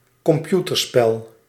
Ääntäminen
IPA: /kɔmˈpjutərspεl/